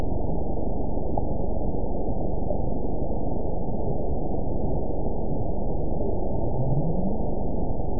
event 914388 date 05/06/22 time 23:16:13 GMT (3 years ago) score 9.59 location TSS-AB05 detected by nrw target species NRW annotations +NRW Spectrogram: Frequency (kHz) vs. Time (s) audio not available .wav